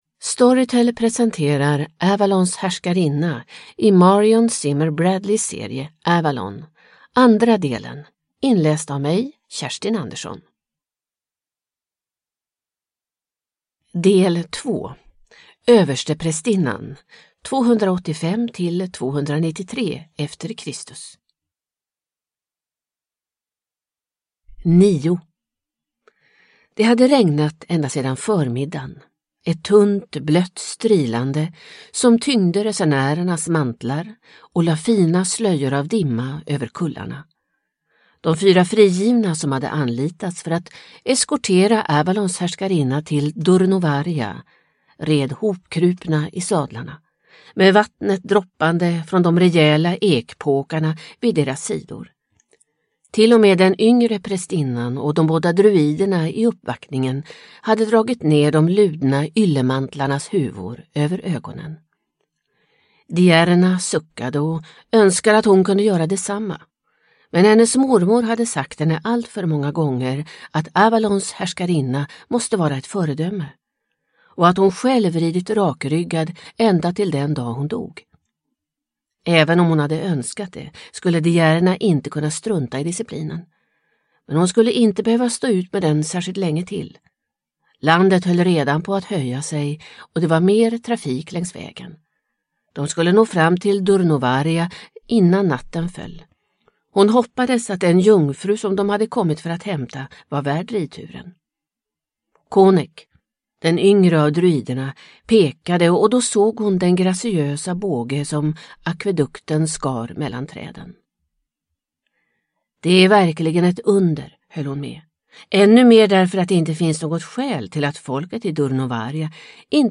Avalons härskarinna. D. 2 – Ljudbok – Laddas ner